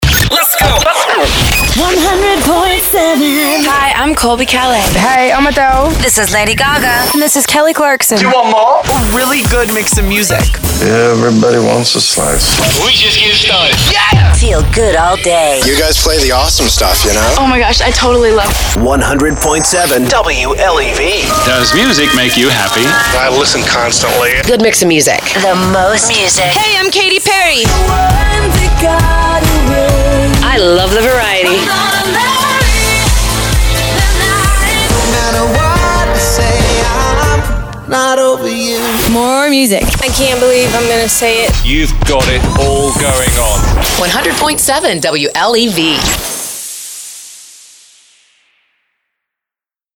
HOT AC
Smart artist material and great listener shouts, delivering fun and energy to your Hot AC. Featuring a variety of topical elements, music beds, on air work parts, jingles and music imaging.